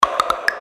без слов
быстрые
Просто звук на сообщения